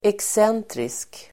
Uttal: [eks'en:trisk]